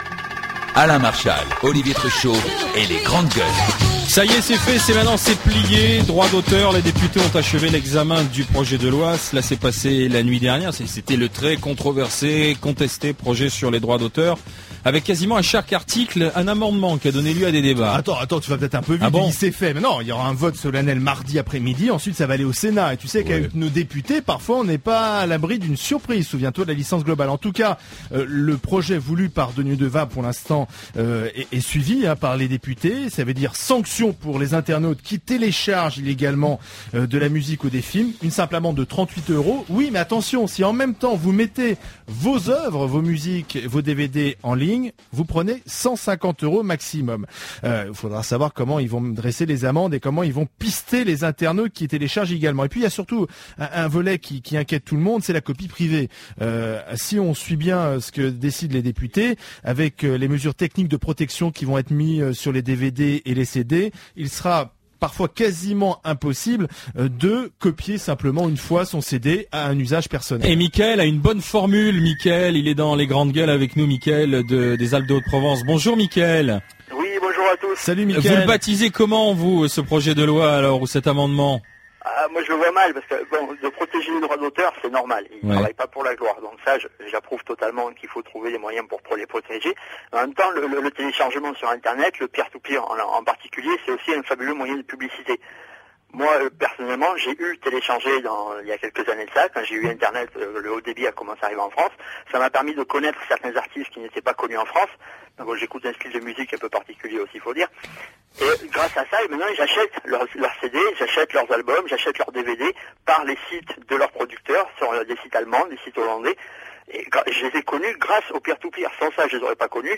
Je suis interviewé sur RMC, dans l’émission Les Grandes Gueules à 13h40 sur les Droits d’auteur, texte qui a finalement été achevé vers 4h ce matin après deux semaines marathons de débats intenses.